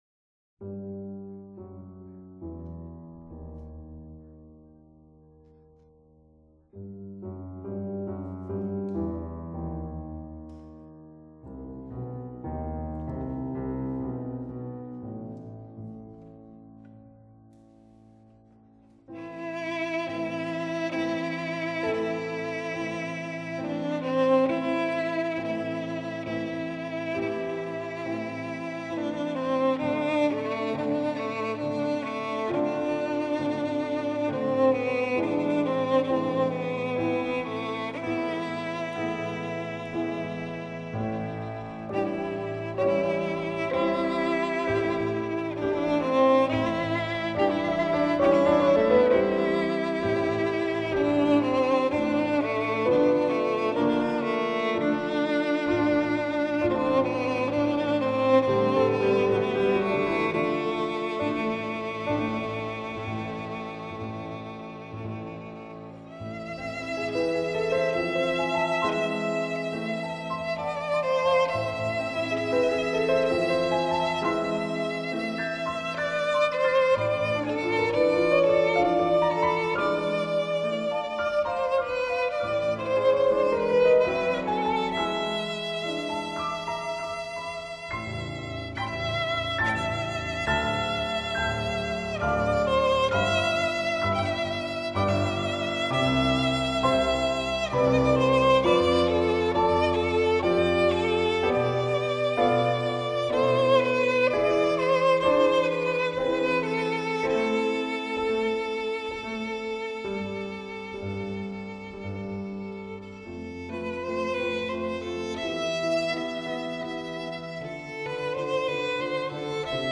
曲调优美，演奏细腻，丝丝入扣
爆发力强，有着惊人的速度和技巧